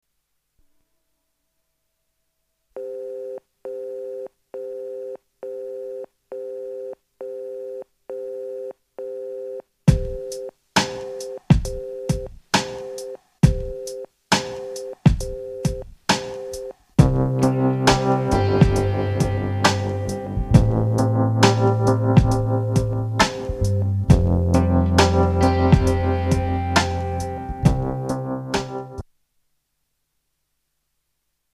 STYLE: Pop
with its slow groove built over a telephone engaged tone!